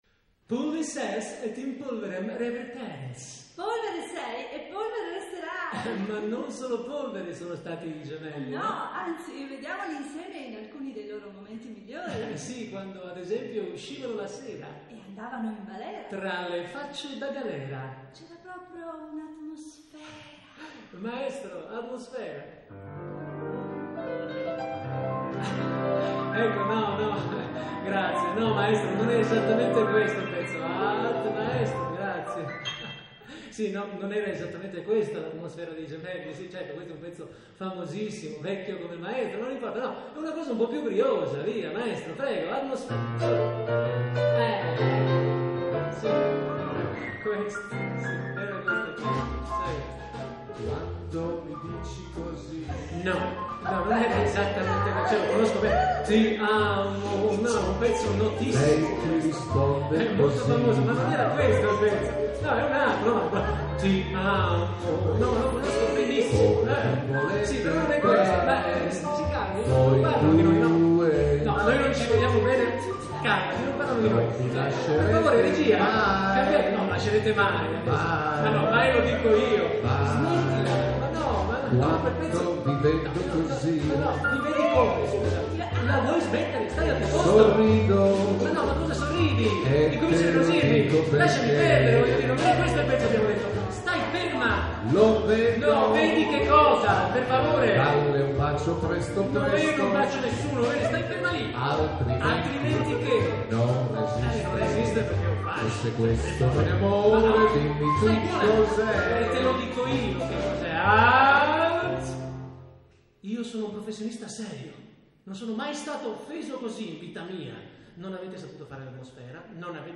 tre cantanti/attori più tre musicisti
piano
Drums
Bass